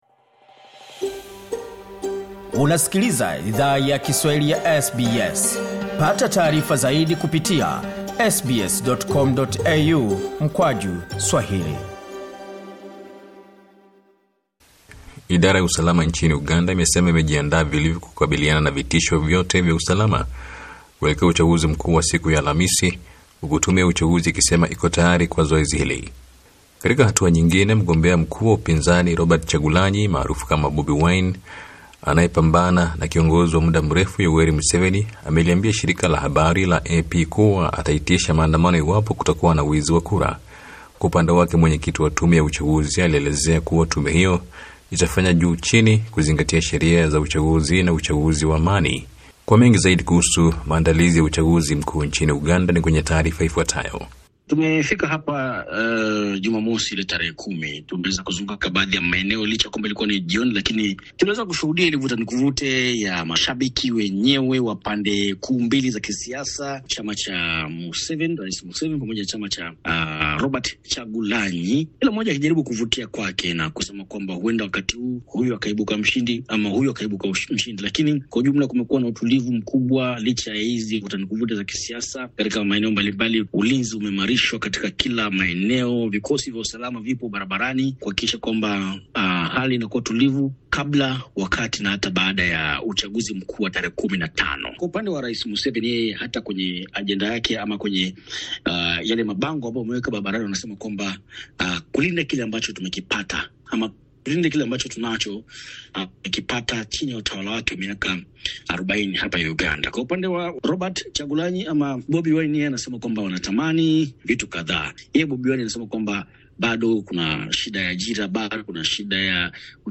Taarifa ya habari:Kevin Rudd ajiuzulu kama balozi wa Australia nchini Marekani